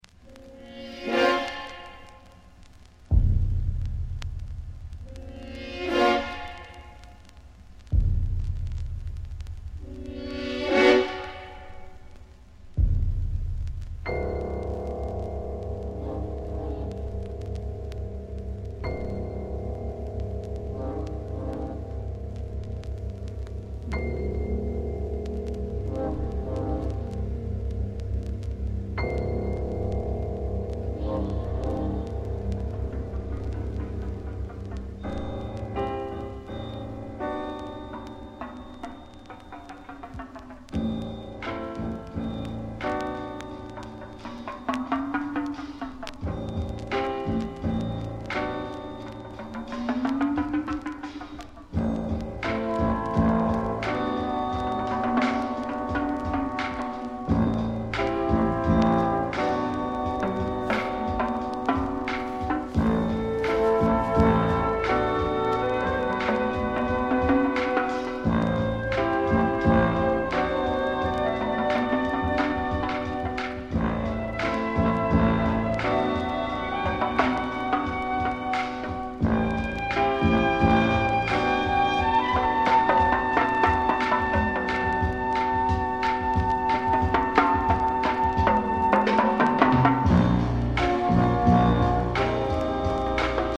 ブレイクビーツ